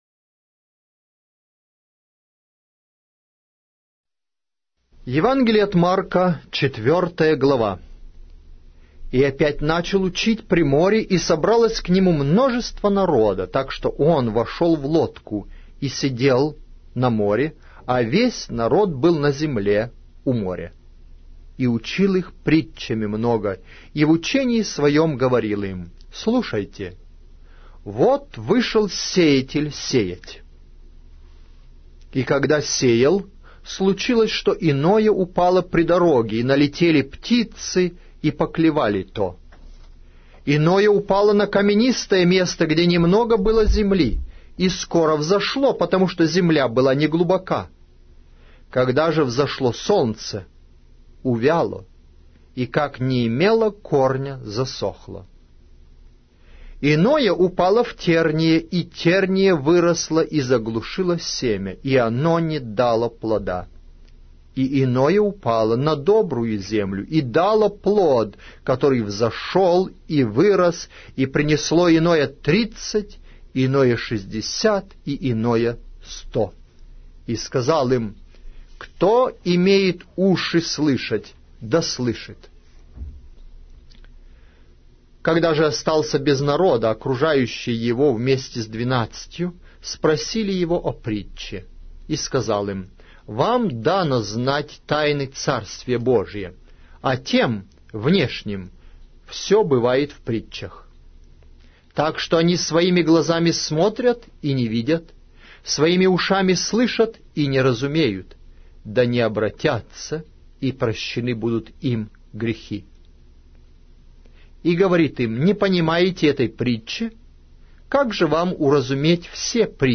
Аудиокнига: Евангелие от Марка